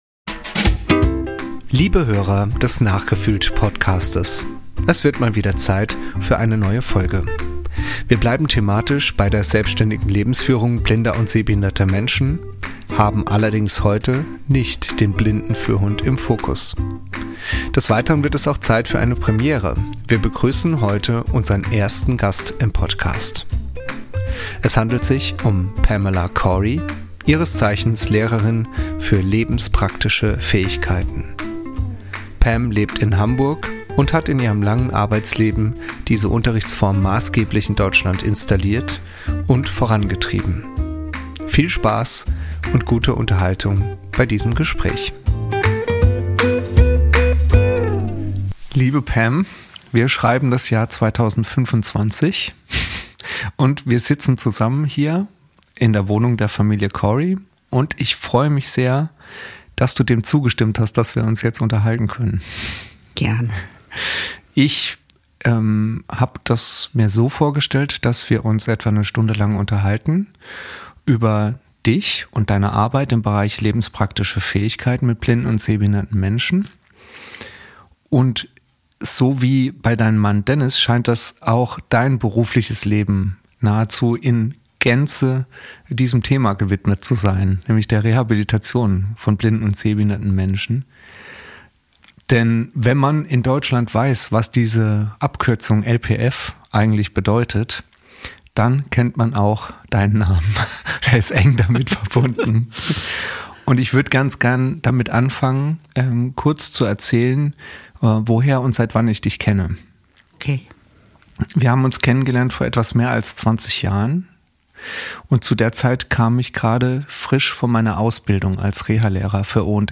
Viel Spaß und gute Unterhaltung bei diesem Gespräch.